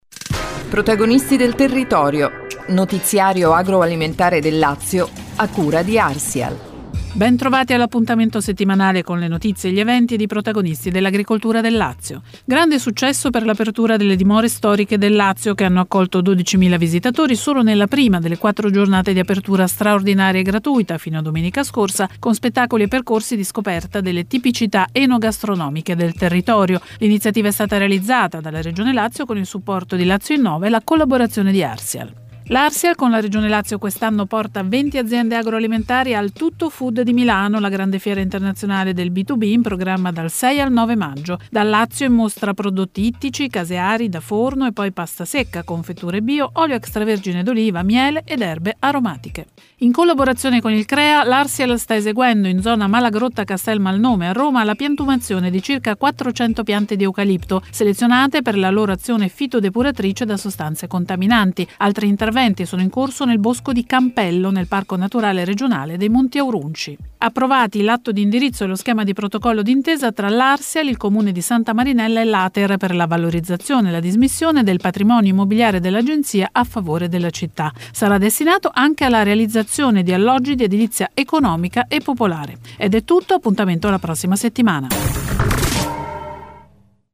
“Protagonisti del territorio”, il notiziario radiofonico Arsial